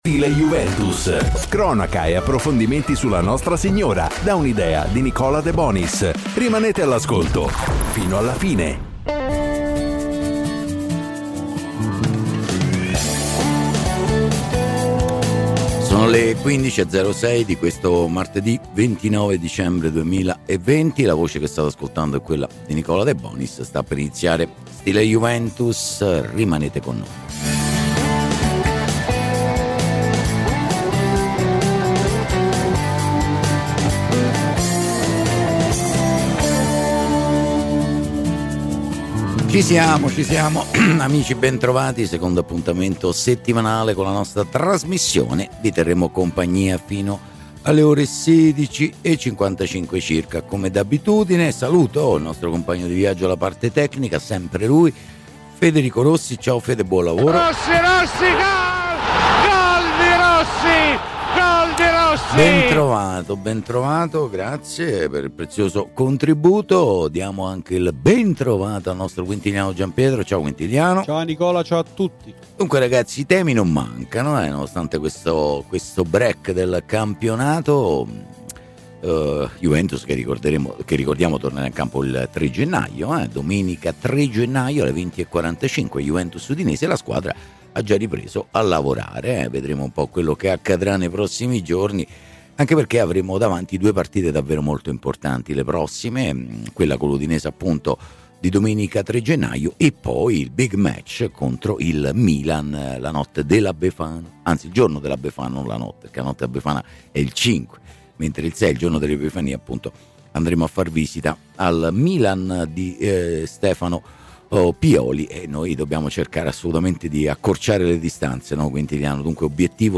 Ai microfoni di Radio Bianconera